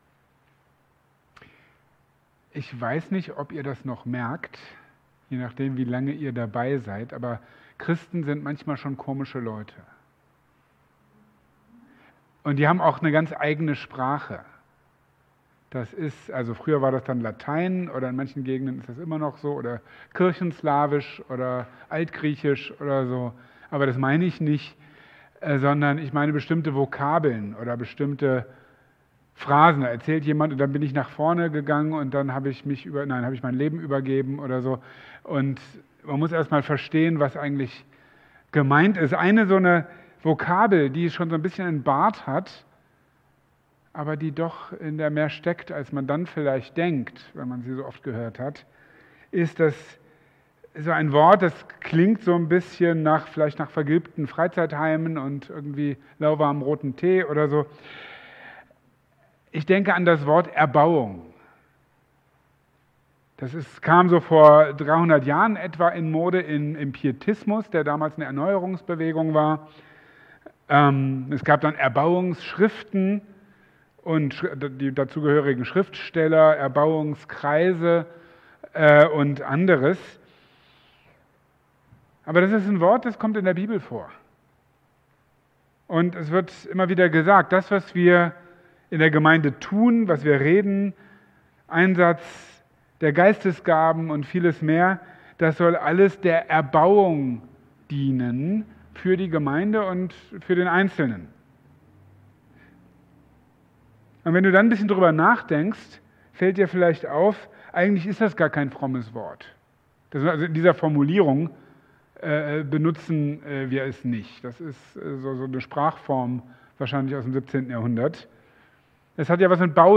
Auf Gottes Baustelle (Epheserbrief, Teil 5) | Marburger Predigten